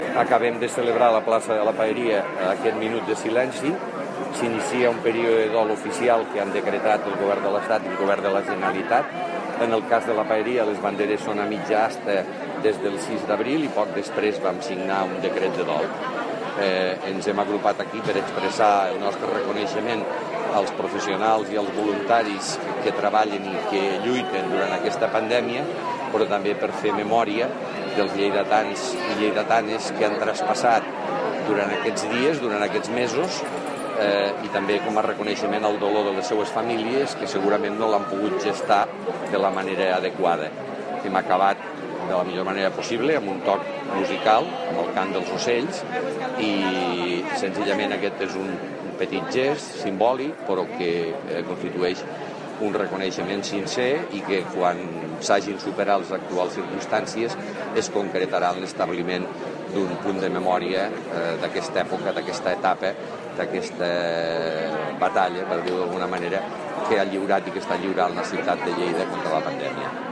Tall de veu alcalde de Lleida
tall-de-veu-de-lalcalde-de-lleida-miquel-pueyo-sobre-el-minut-de-silenci-en-record-de-les-victimes-de-la-covid-19